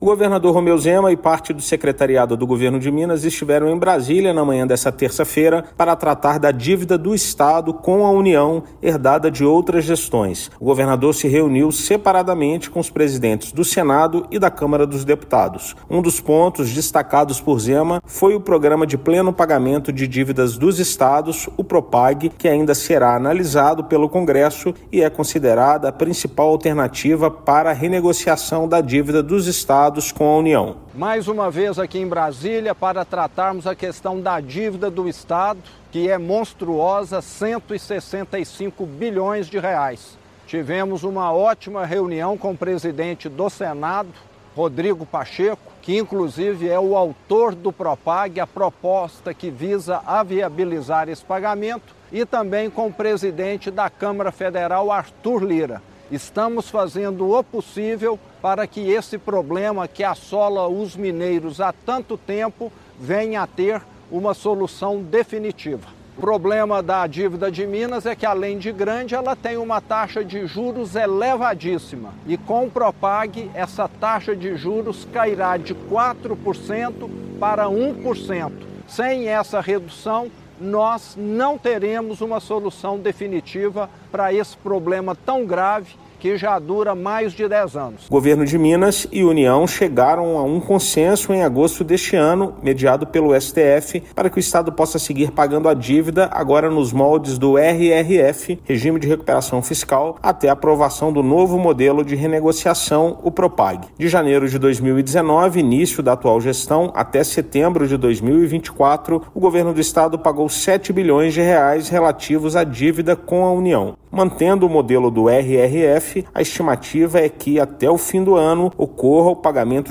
Chefe do Executivo estadual esteve novamente na capital federal para tratar da tramitação do Programa de Pleno Pagamento de Dívidas dos Estados (Propag). Ouça matéria de rádio.